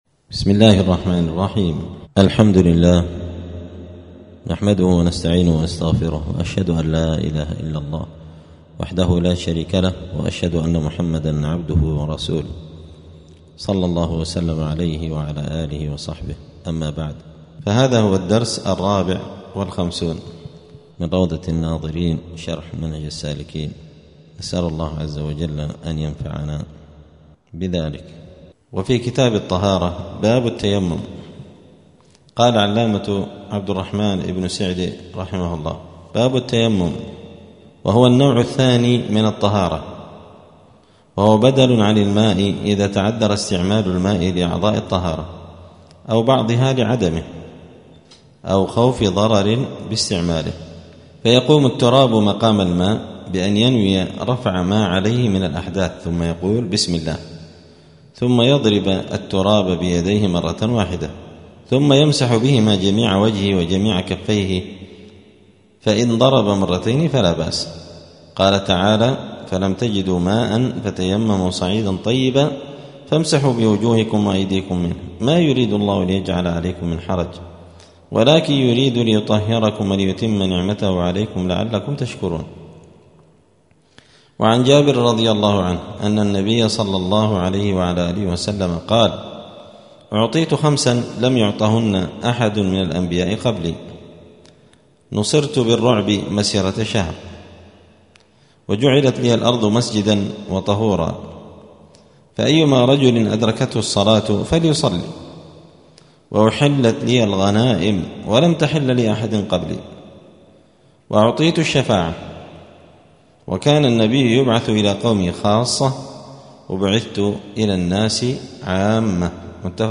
*الدرس الرابع والخمسون (54) {كتاب الطهارة باب التيمم}*
دار الحديث السلفية بمسجد الفرقان قشن المهرة اليمن